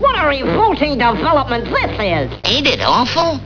Bugs and Daffy Comments - Free Sound Download (37.3 KB) (.wav)